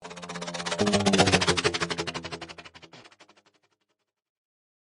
Sax Flutter Blow
Sax Flutter Blow is a free music sound effect available for download in MP3 format.
Sax Flutter Blow.mp3